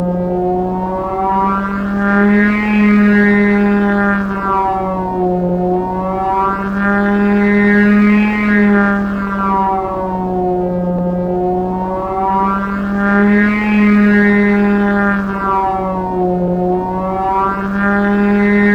Index of /90_sSampleCDs/E-MU Producer Series Vol. 3 – Hollywood Sound Effects/Science Fiction/Scanners
SCANNER 5-L.wav